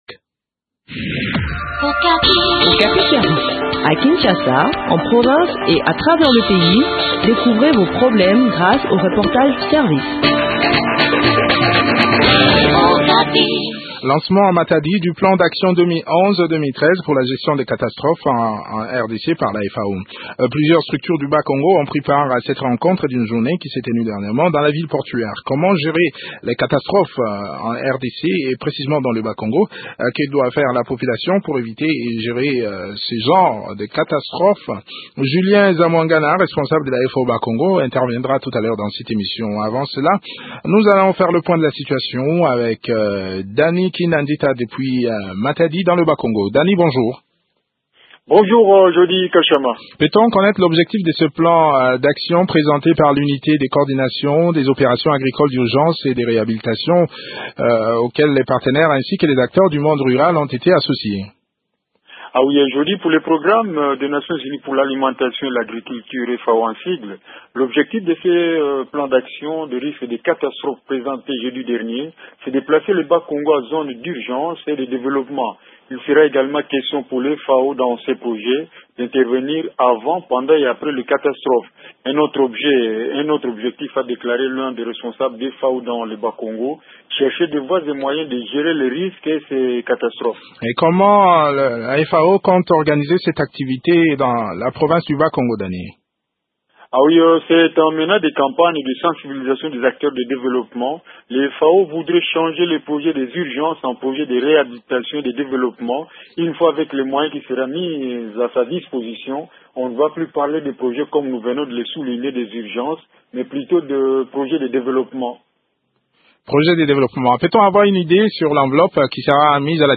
répond au micro de